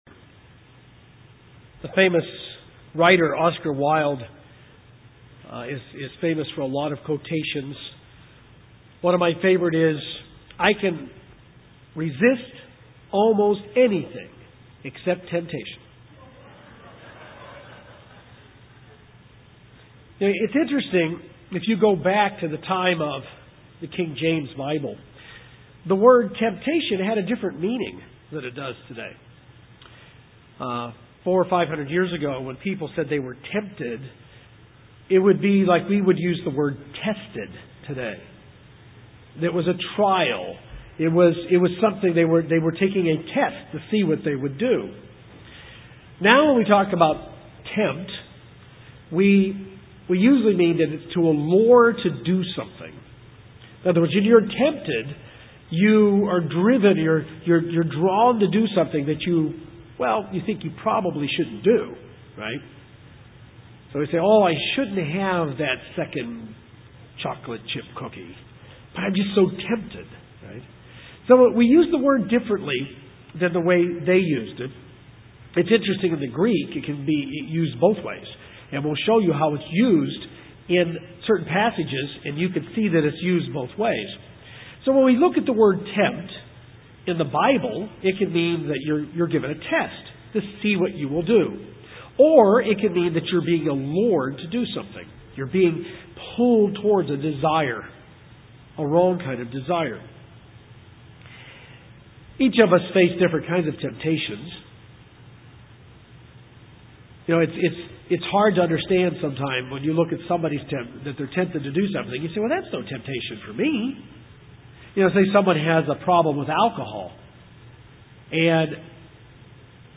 This sermon helps us discover how to deal with temptation. We will learn what it is and what happens to us when we are tempted and where temptation comes from.